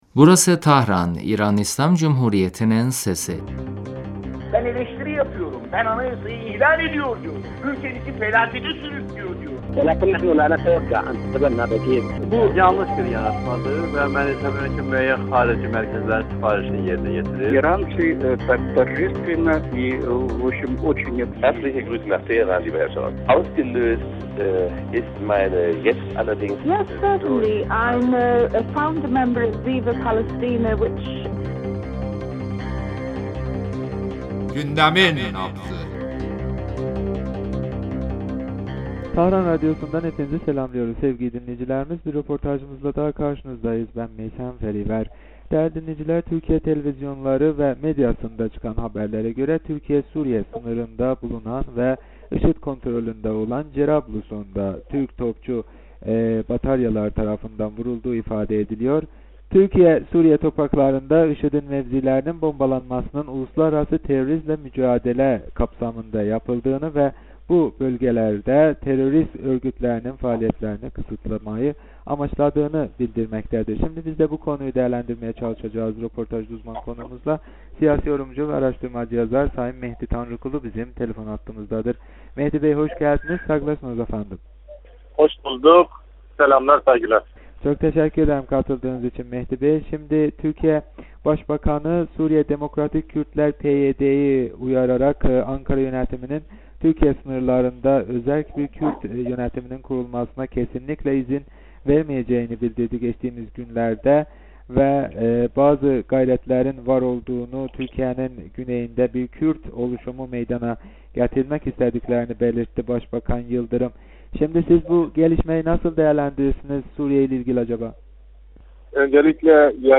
Röportajımızda